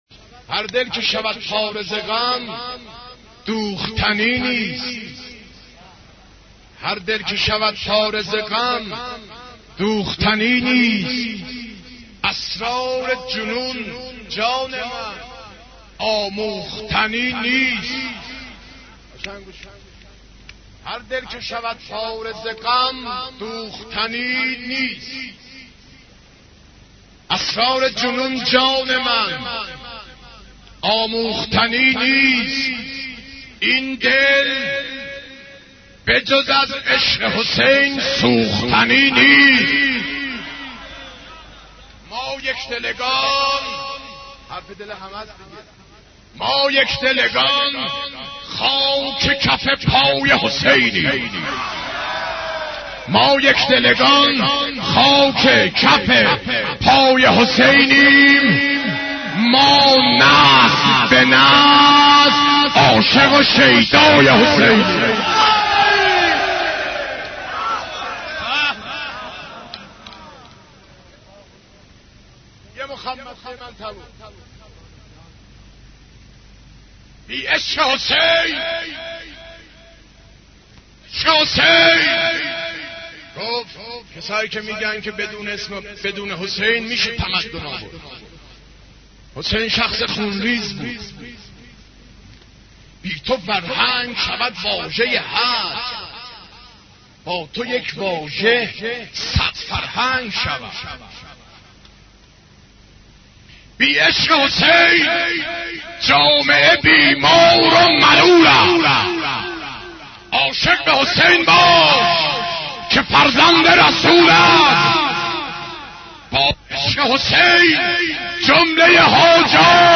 مداحی امام حسین ع 12